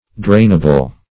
Drainable \Drain"a*ble\, a. Capable of being drained.